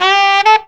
COOL SAX 5.wav